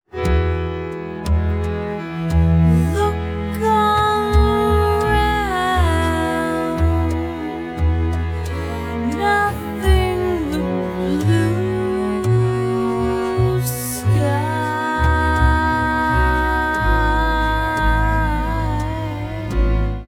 jazz-20-prop.wav